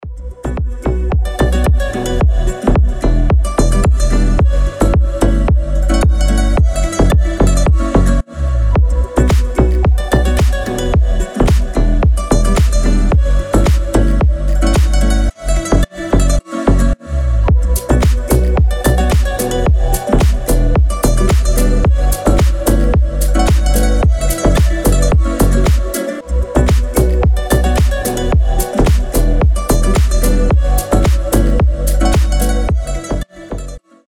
• Качество: 320, Stereo
гитара
deep house
без слов
красивая мелодия
Очень приятная мелодия на рингтон